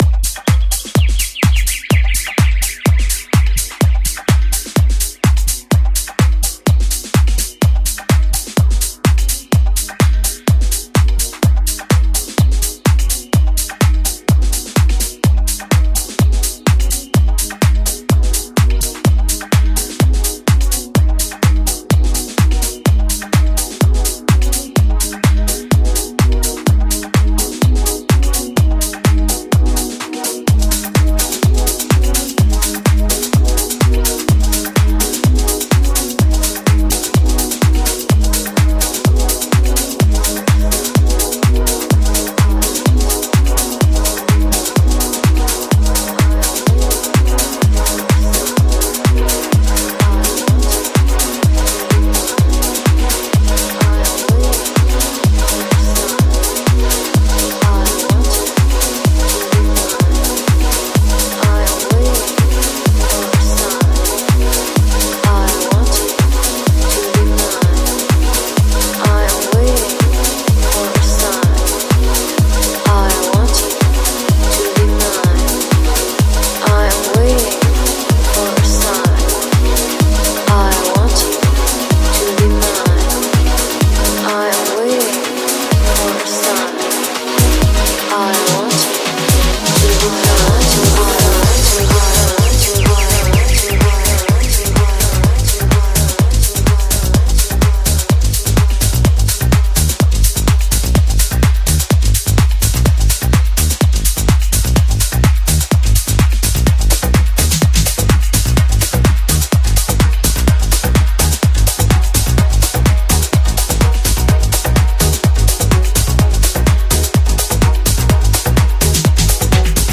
Progressive House